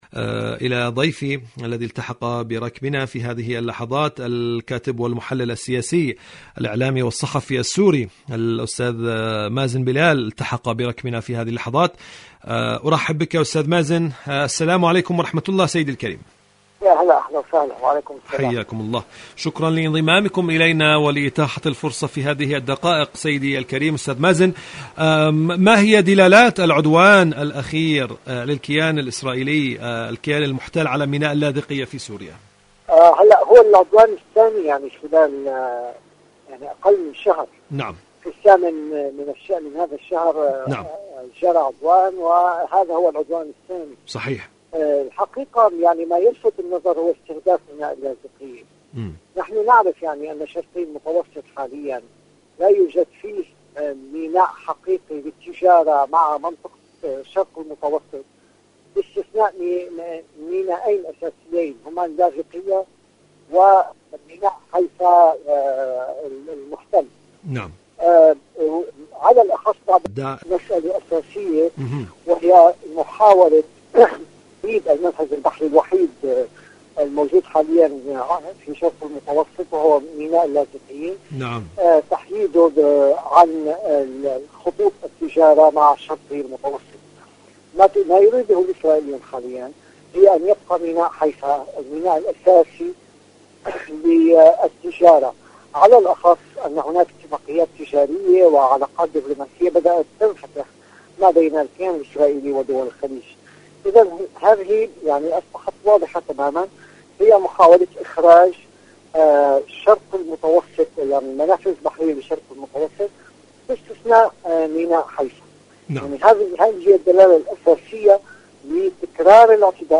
إذاعة طهران-حدث وحوار: مقابلة إذاعية